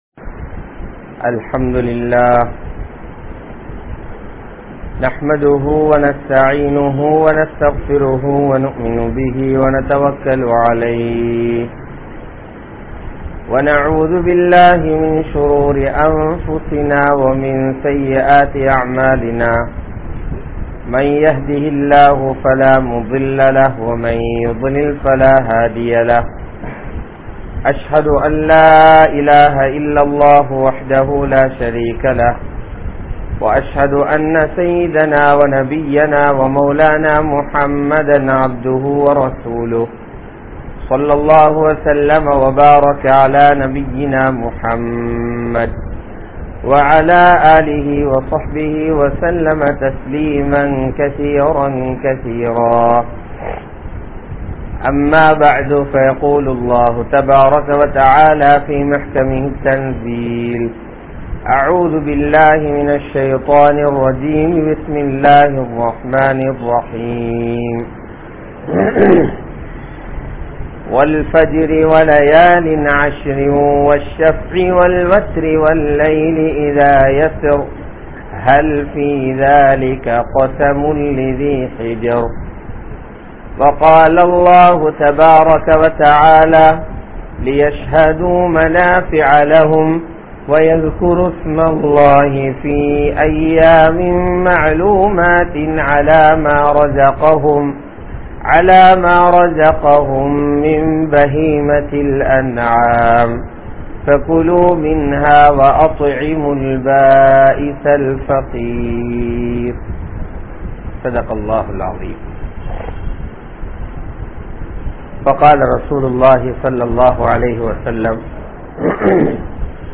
First 10 Days of Dhul Hijjah | Audio Bayans | All Ceylon Muslim Youth Community | Addalaichenai
Muhiyaddeen Grand Jumua Masjith